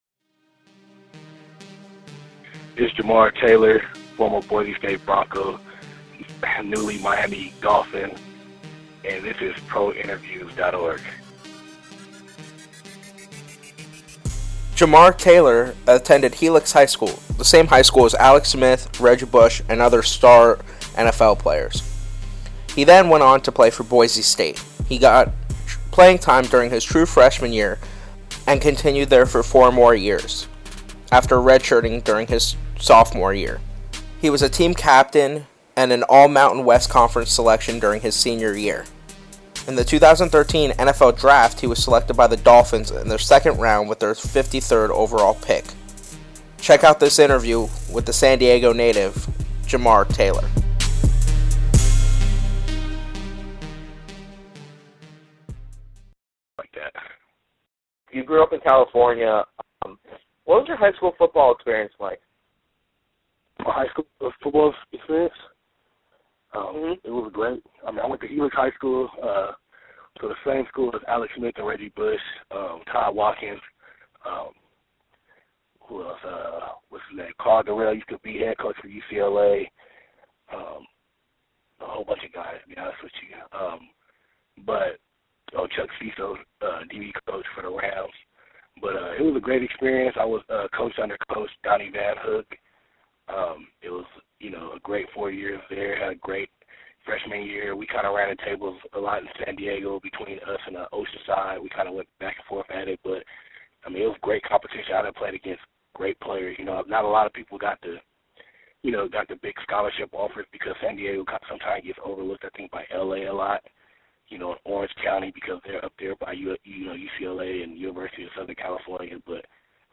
Newly Drafted Dolphins Cornerback, Jamar Taylor Interview
2nd round pick for the Miami Dolphins, CB Jamar Taylor joins us for an exclusive one-on-one interview. Jamar Taylor talks about growing up in the rough area of San Diego, his time at Boise State, his offseasons, and what it was like to be drafted by the Dolphins.
jamar-taylor-interview.mp3